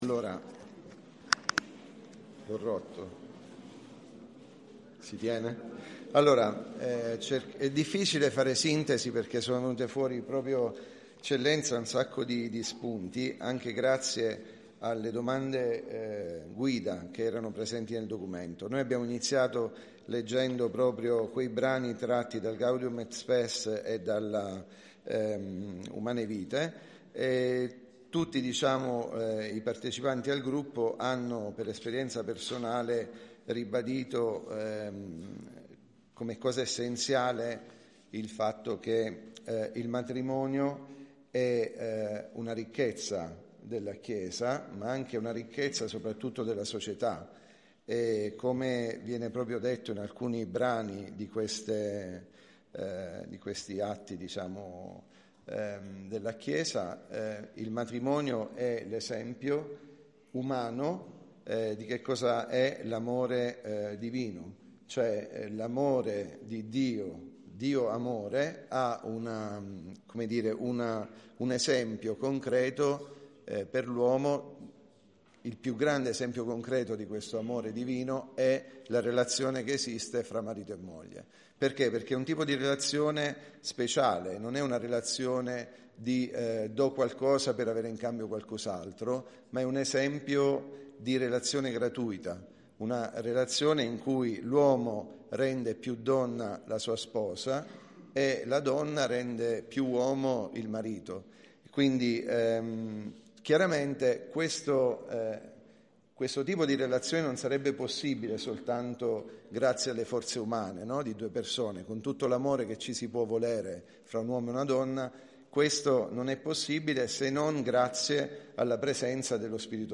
Si è tenuto sabato 22 giugno 2024 l’annuale Convegno Diocesano, appuntamento programmatico che riunisce tutte le realtà della Chiesa di Teramo-Atri per tracciare un bilancio dei dodici mesi appena trascorsi e delineare il cammino da intraprendere insieme nell’immediato futuro.
Relazione Gruppo 2 – Pastorale della famiglia la profezia dell’amore coniugale